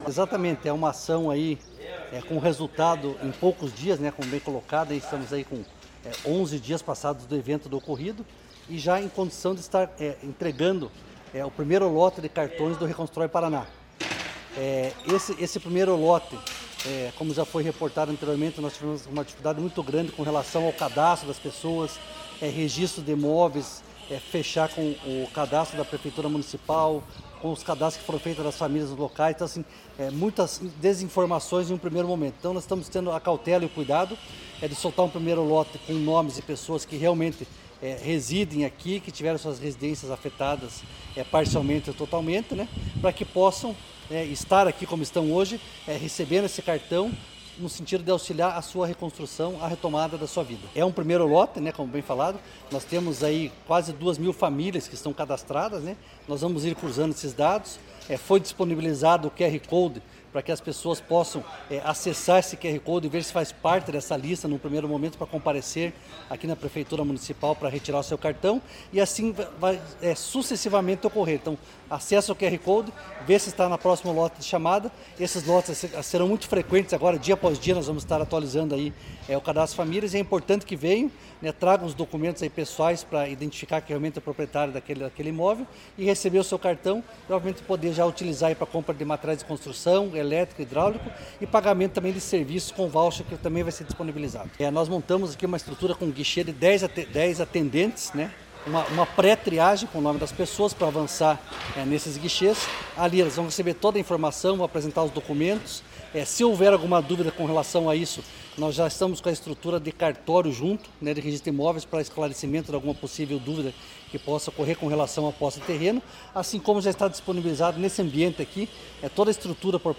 Sonora do coordenador da Defesa Civil Estadual, coronel Fernando Schunig, sobre a entrega dos primeiros cartões do programa Reconstrução